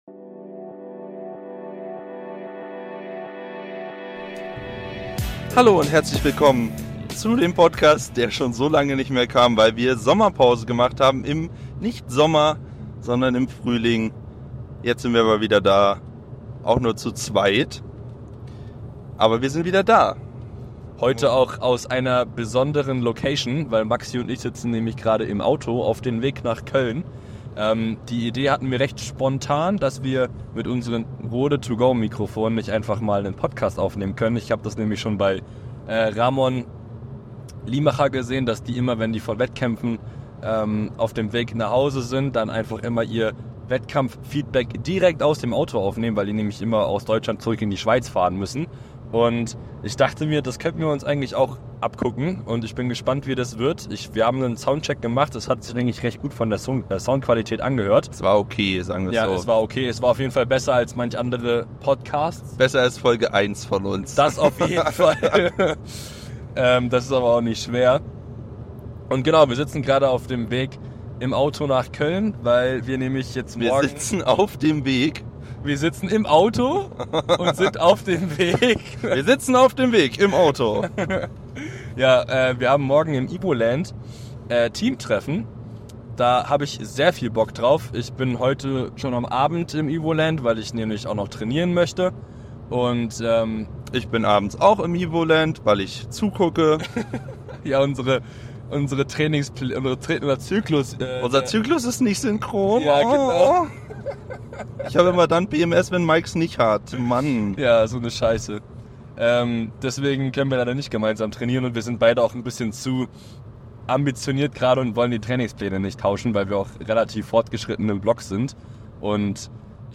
Zwar aus dem Auto aufgenommen und nur zu zweit aber wir hoffen, es gefällt euch trotzdem.